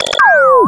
GBotConcern.wav